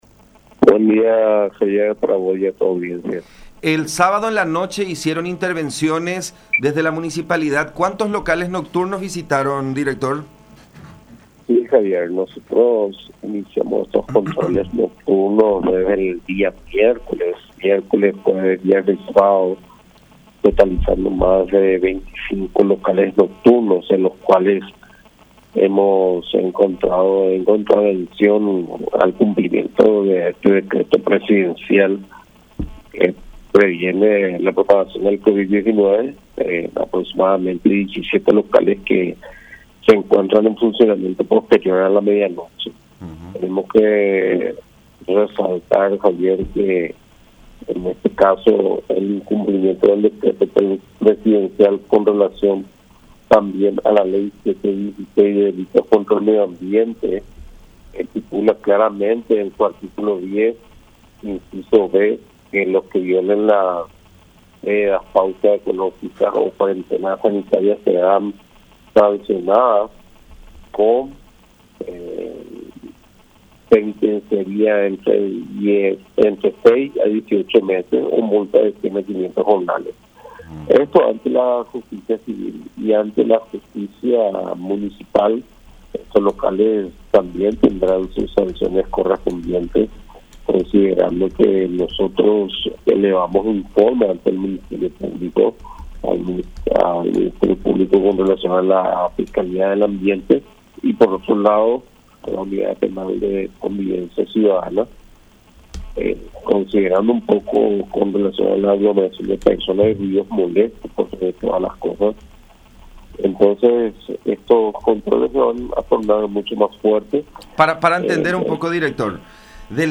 en conversación con La Unión.